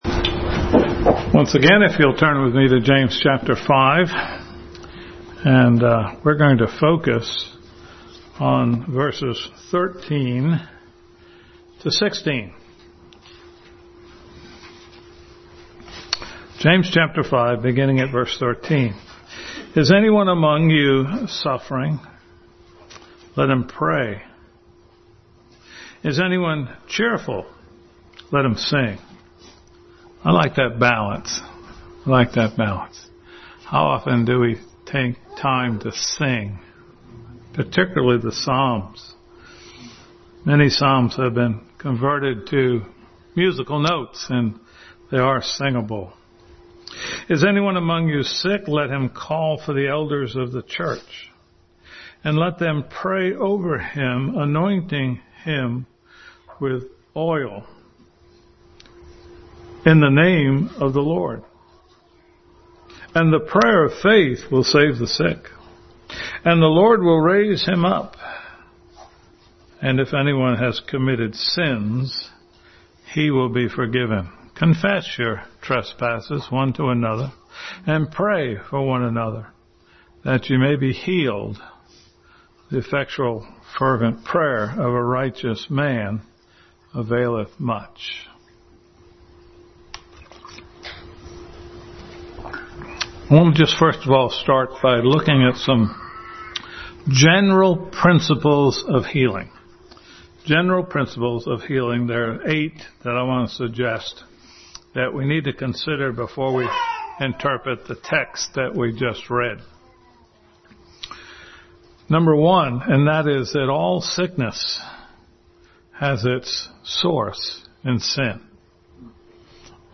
James 5:13-16 Passage: James 5:13-16, Hebrews 13:7, 17, Matthew 6:8-12, Luke 10:34 Service Type: Family Bible Hour